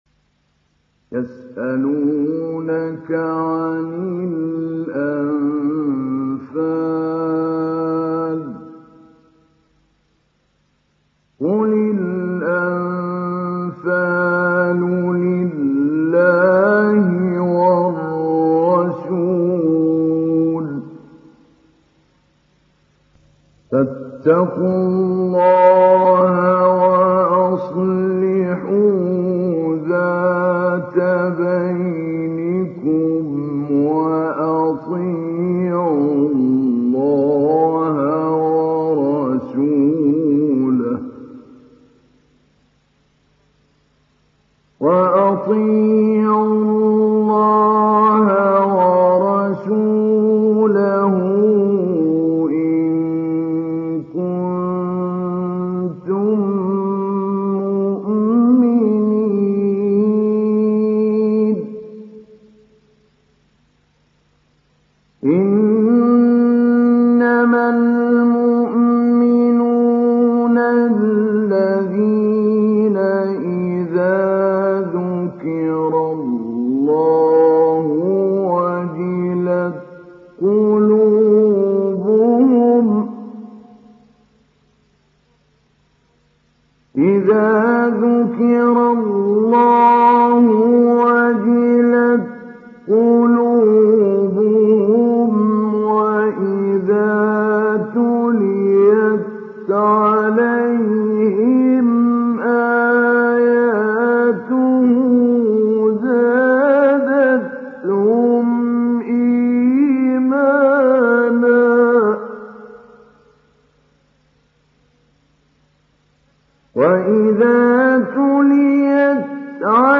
ডাউনলোড সূরা আল-আনফাল Mahmoud Ali Albanna Mujawwad